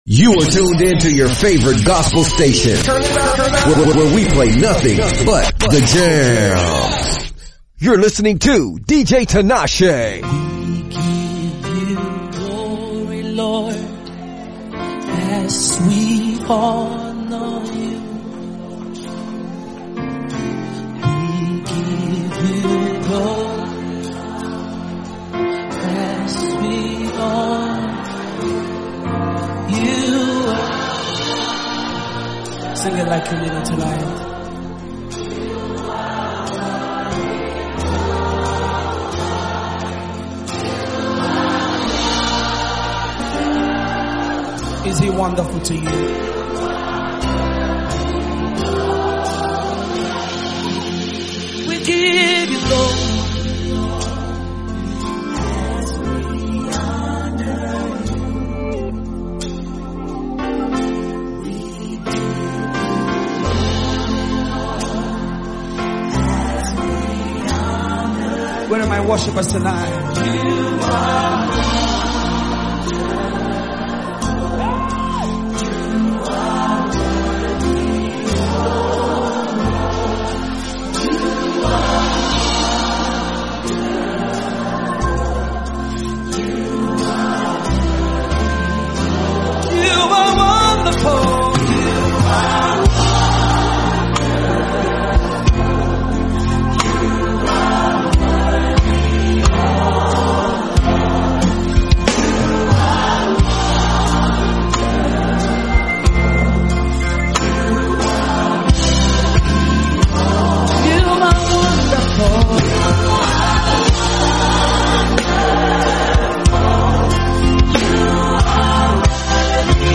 Dj Mix